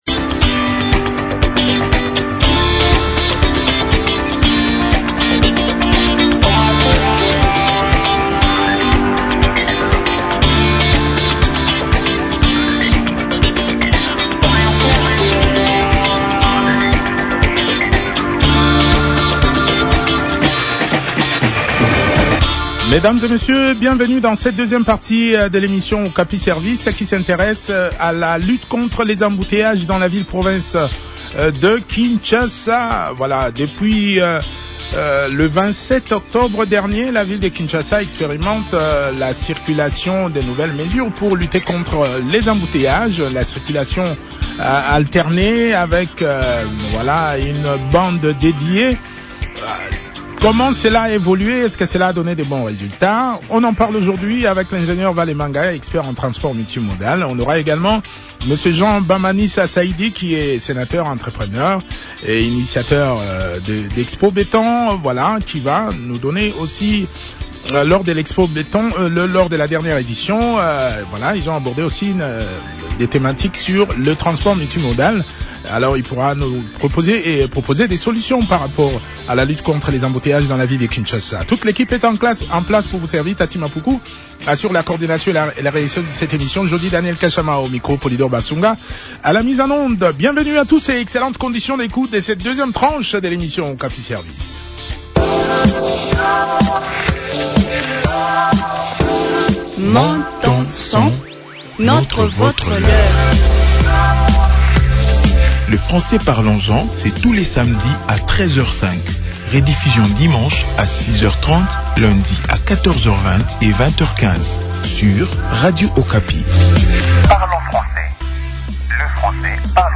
expert en transport multimodal.
Honorable Jean Bamanisa a aussi pris part à cet entretien.